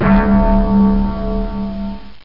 Gong Sound Effect
Download a high-quality gong sound effect.
gong.mp3